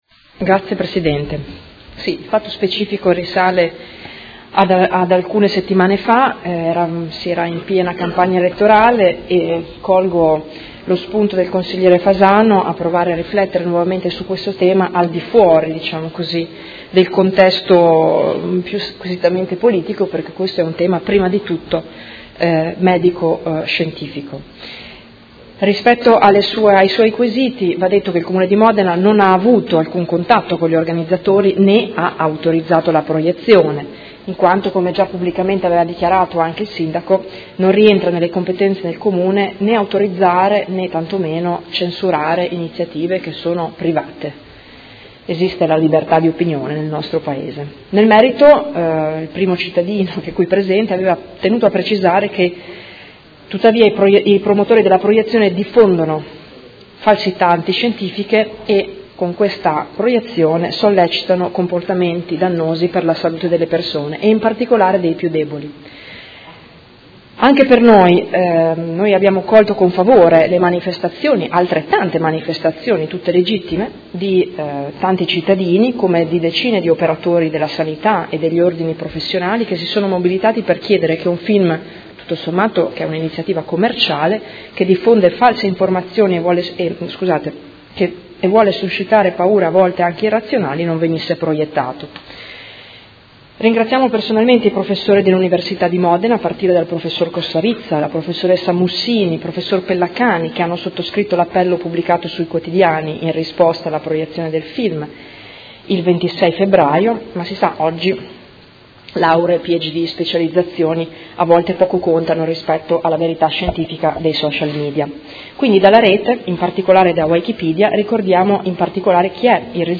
Giuliana Urbelli — Sito Audio Consiglio Comunale